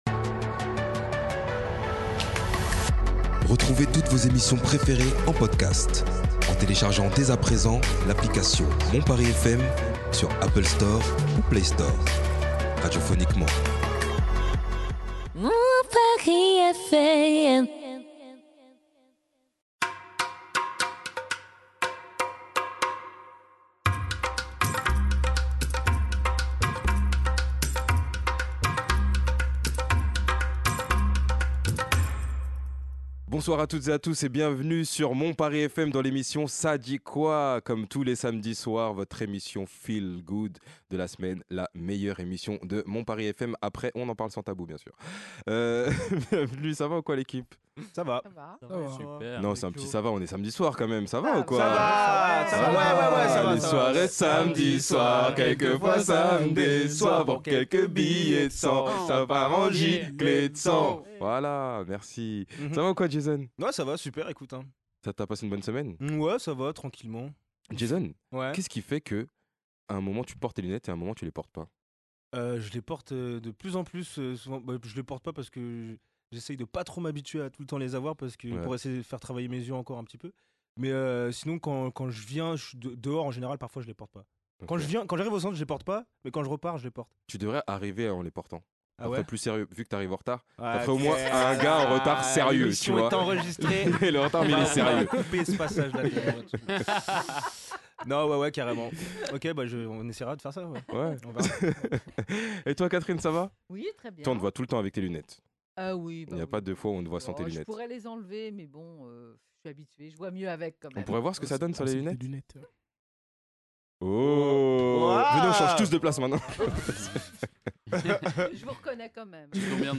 Débat de la semaine